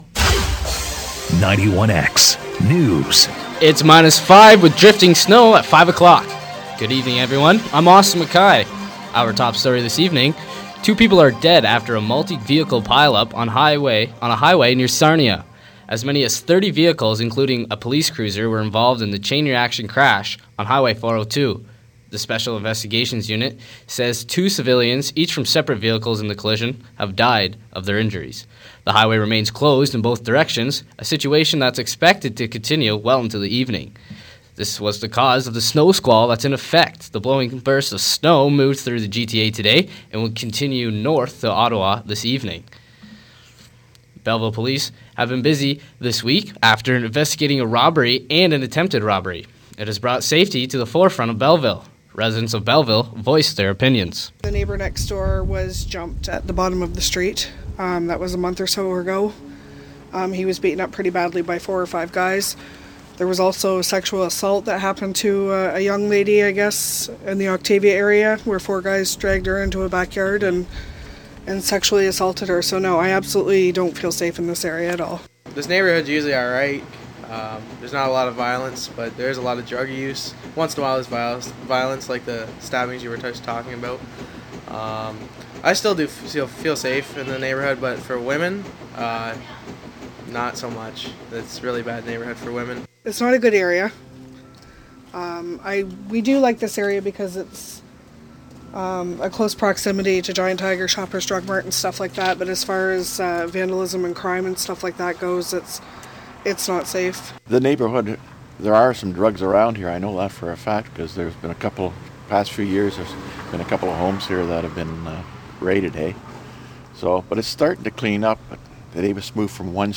A deadly chain reaction crash near Sarina was one of many accidents due to the weather. Assaults, robbery and violence scare residents in Belleville. Wind farms are on the minds of many in Prince Edward County. For these stories sports and more listen to the 5pm newscast.